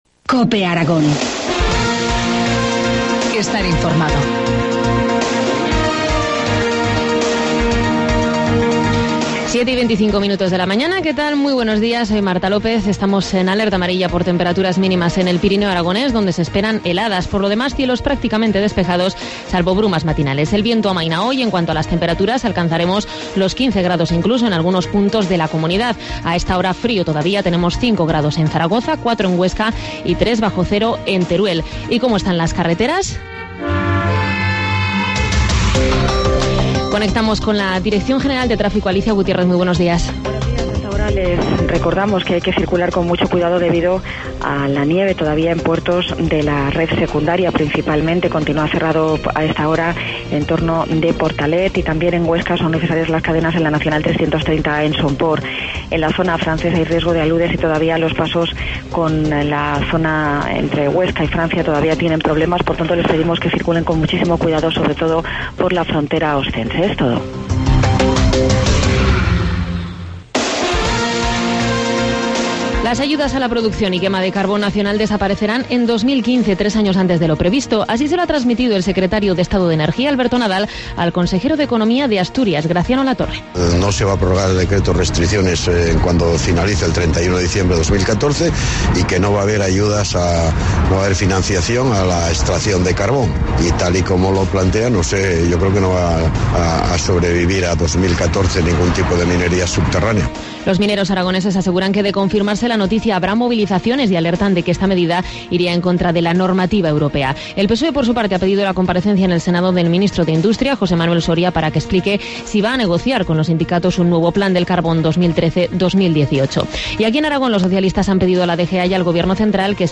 Informativo matinal, martes 29 de enero, 7.25 horas